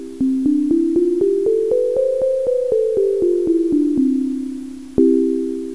9月7日 10月号関係で作ったPSoC電子オルゴールの
doremi.wav